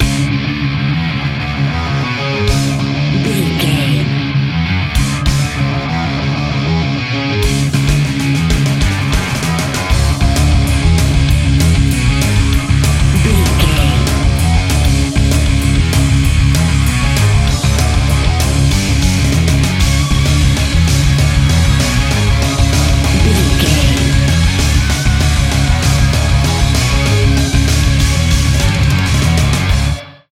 Epic / Action
Fast paced
Aeolian/Minor
C♯
Fast